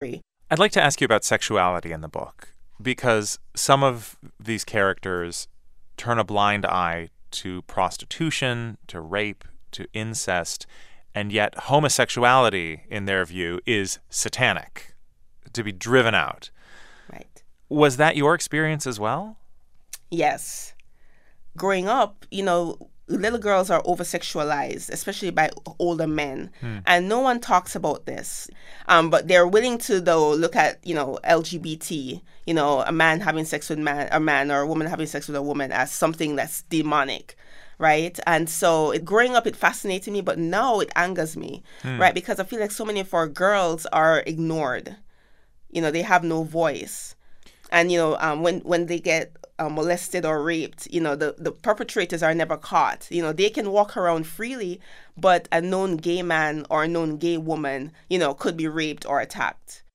An interview with Nicole Dennis-Benn conducted by Ari Shapiro of the National Public Radio